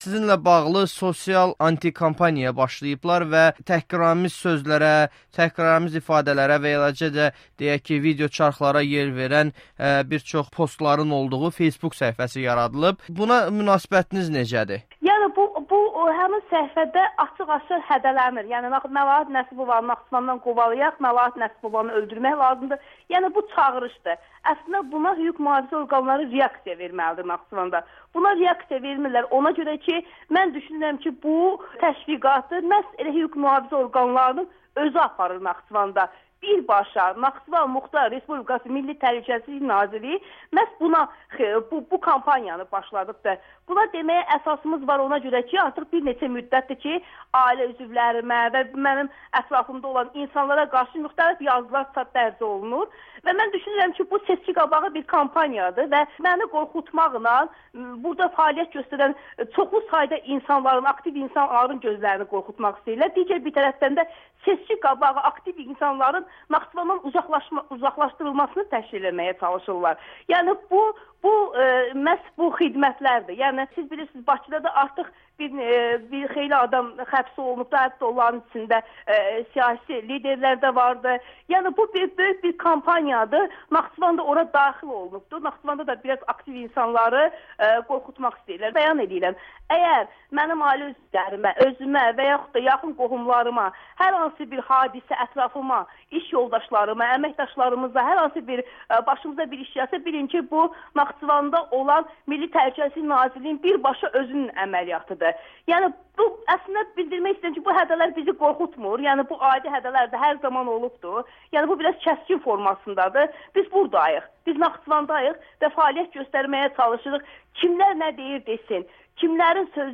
sosial anti-kampaniya barədə Amerikanın Səsinə müsahibəsi